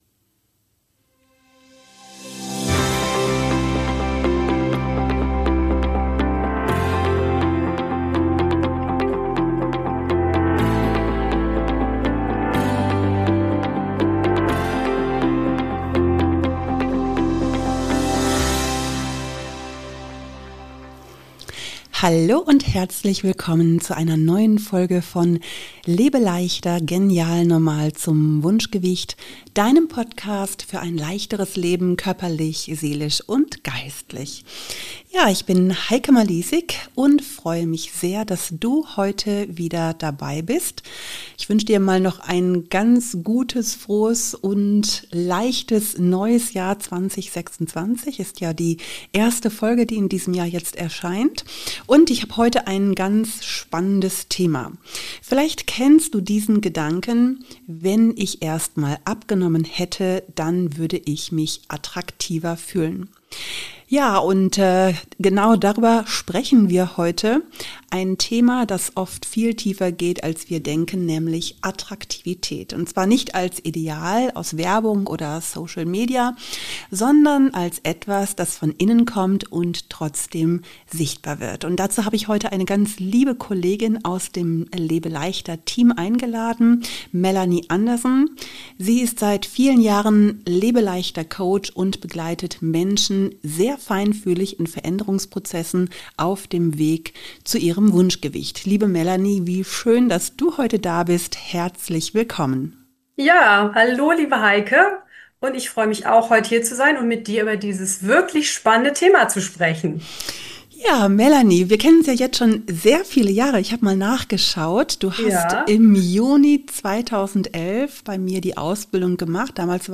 Wir sprechen darüber, warum Attraktivität nichts mit Kleidergröße zu tun hat, wie Ausstrahlung entsteht, warum Selbstannahme kein Ziel „für später“ ist und was du heute schon für dich tun kannst.